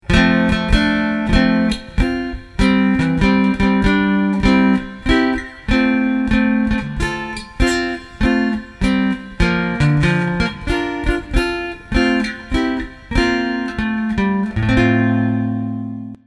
Traditional
Trascrizione e arrangiamento per chitarra
Tecnicamente, l'arrangiamento di Happy Birthday che vedete sotto è costruito armonizzando sulla linea melodica gli accordi ad essa inerenti, senza andare a ricercare particolari sonorità, ma rimanendo il più possibile fedeli all'originale.